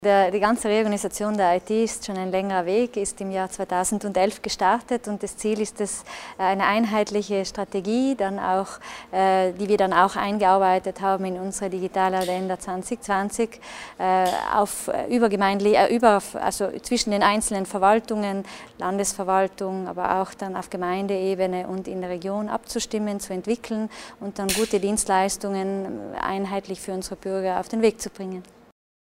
Landesrätin Waltraud Deeg spricht über die Zukunft des Informatikbereiches in Südtirol